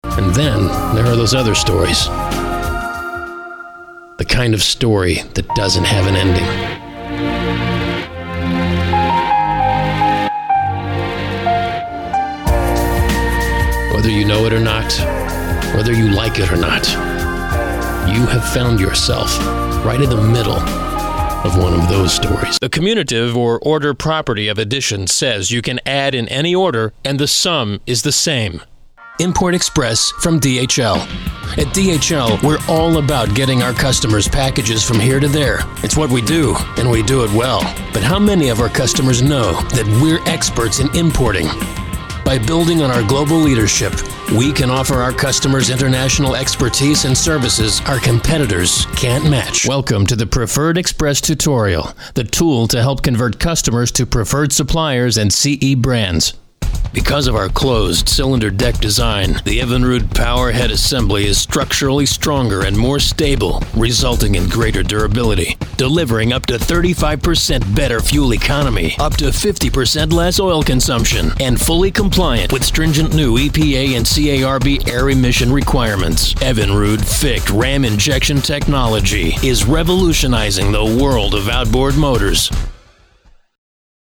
Male Voice Overs